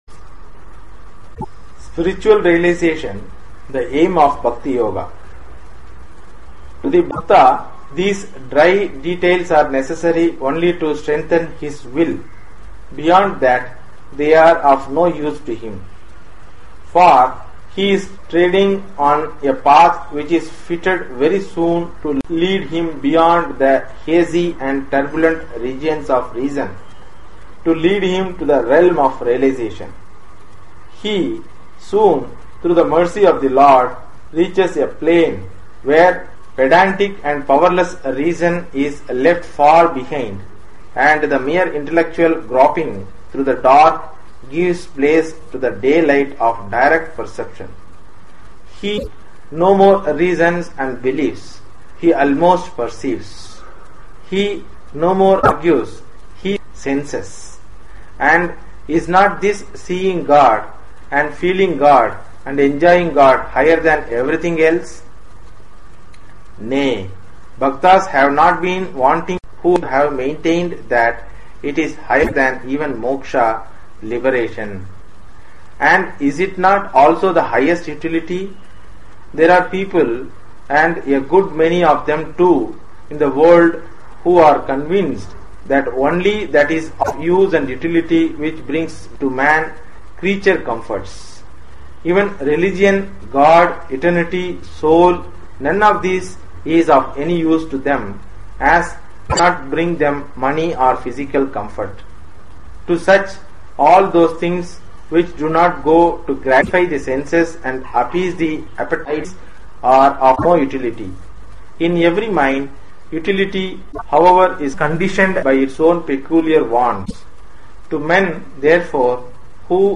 Readings from the Complete Works of Swami Vivekananda (29)